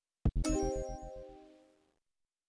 short_sweet.wav